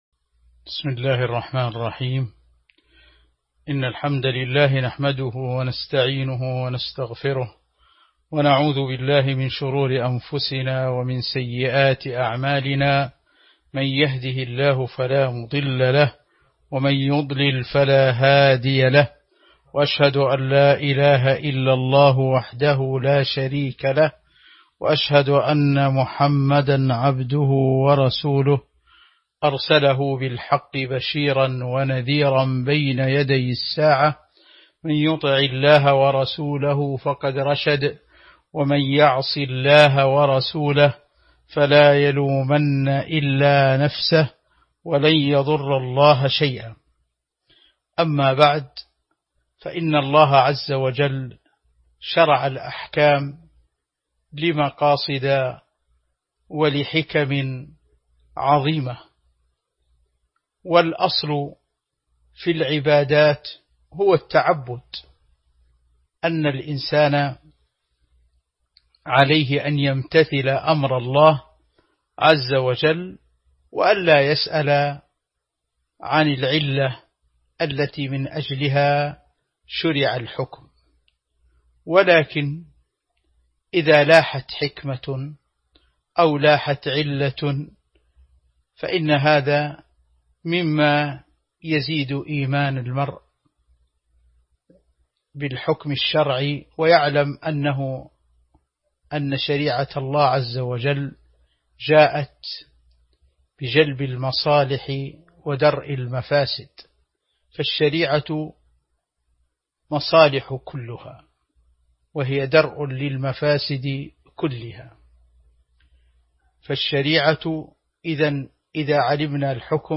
تاريخ النشر ٤ ذو الحجة ١٤٤١ هـ المكان: المسجد النبوي الشيخ